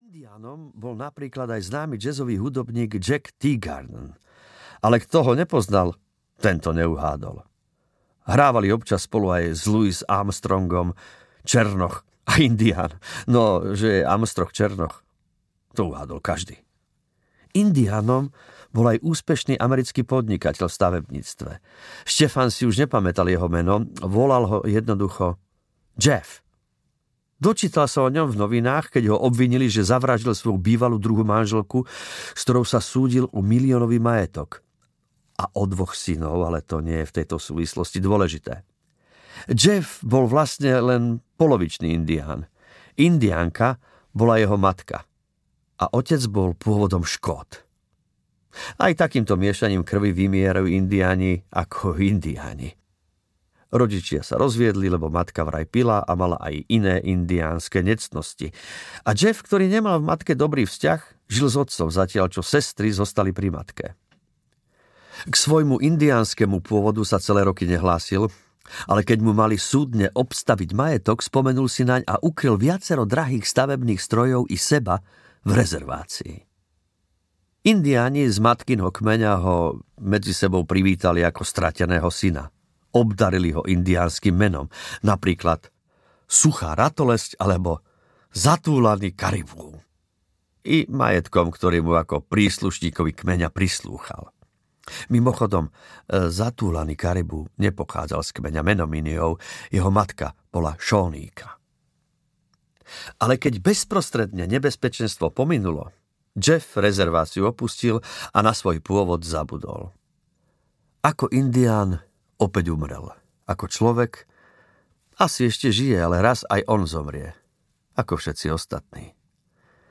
Letmý sneh audiokniha
Ukázka z knihy
• InterpretAdy Hajdu